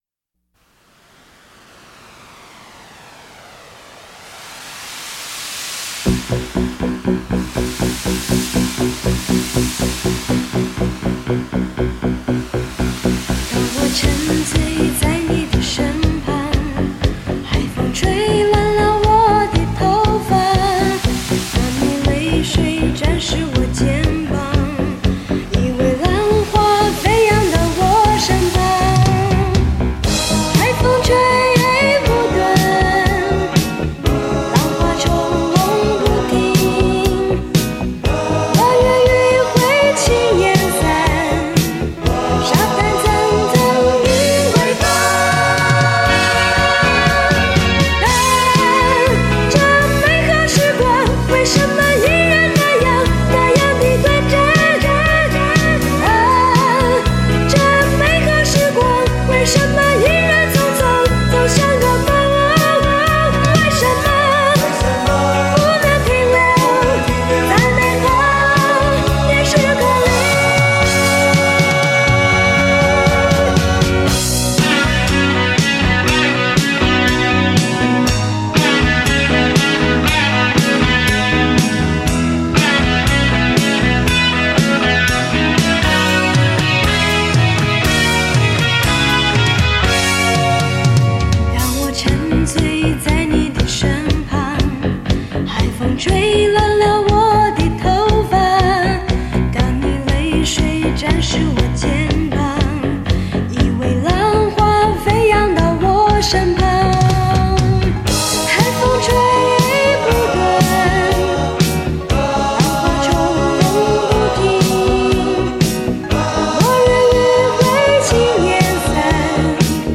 或抒情，或轻摇滚